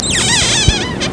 1 channel
squeak2.mp3